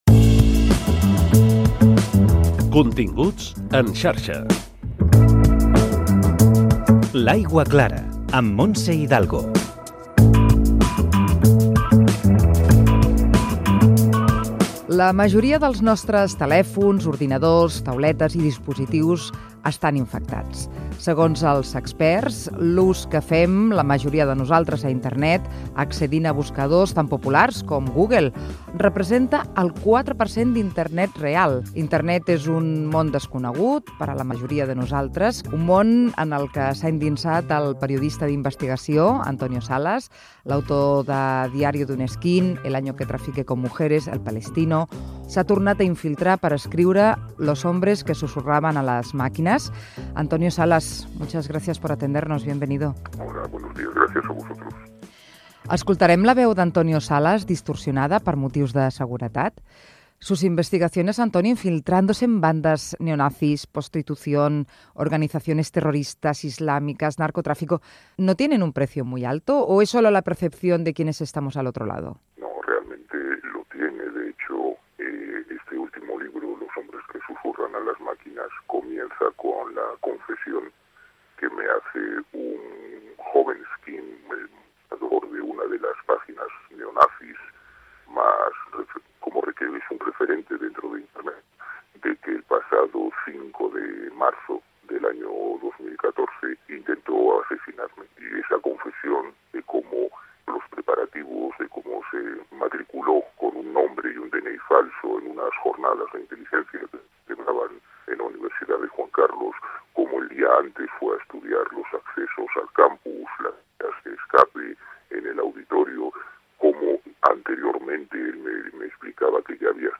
Secció "L'aigua clara". Fragment d'una entrevista al periodista Antonio Salas, autor de "Los hombres que susurraban a las máquinas". Per seguretat la veu de l'entrevistat està distorsionada.
Divulgació